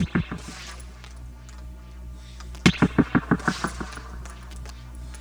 Back Alley Cat (Pecussion FX 01).wav